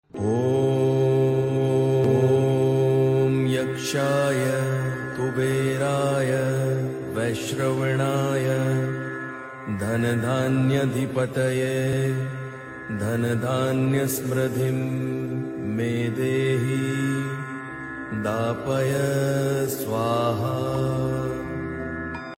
Chanting this mantra is believed to bring material abundance, financial stability, and remove money-related obstacles.